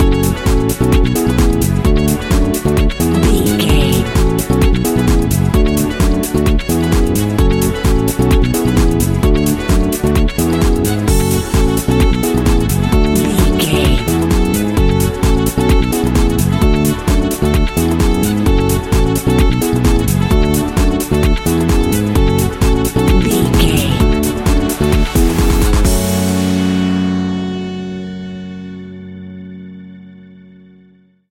A great piece of royalty free music
Aeolian/Minor
F#
uplifting
energetic
funky
saxophone
bass guitar
drums
synthesiser
electric organ
funky house
disco
upbeat